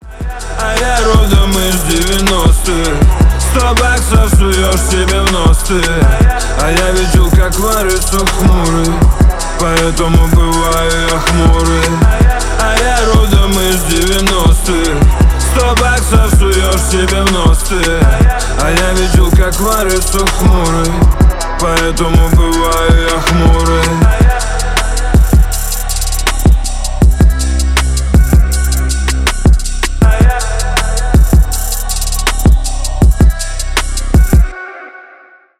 блатные
рэп